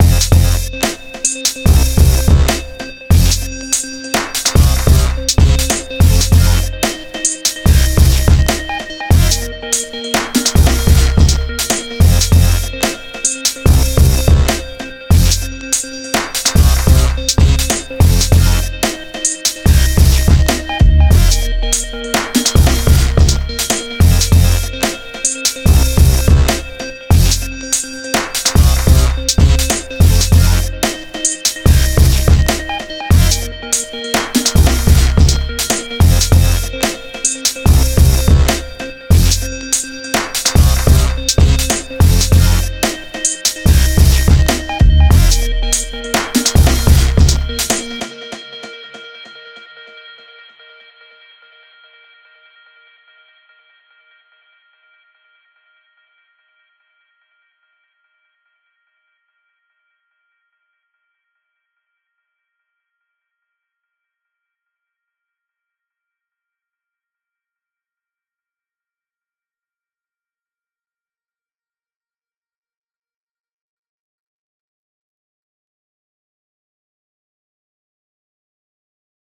Tuesday morning commute managed to turn out a decent riff on the Move: